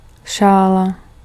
Ääntäminen
Synonyymit foulard cache-nez Ääntäminen France: IPA: [e.ʃaʁp] Haettu sana löytyi näillä lähdekielillä: ranska Käännös Ääninäyte Substantiivit 1. šerpa {m} Muut/tuntemattomat 2. šála {f} Suku: f .